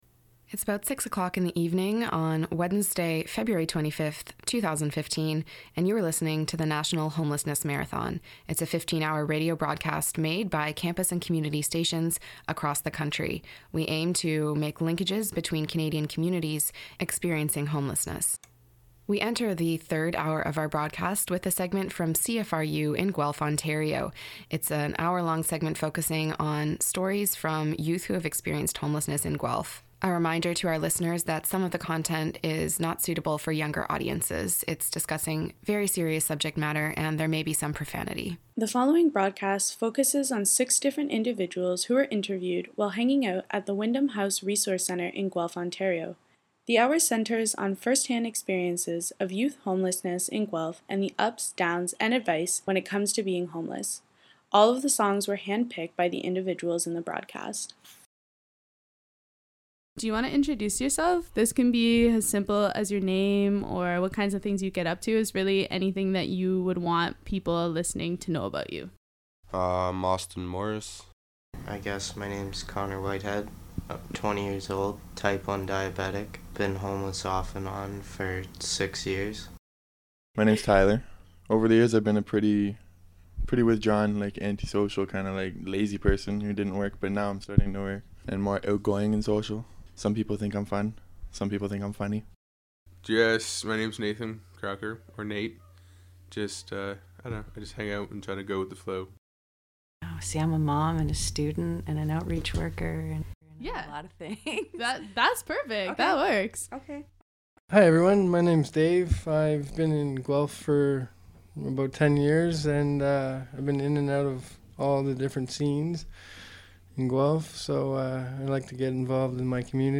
Interviews with youth exploring homelessness & services available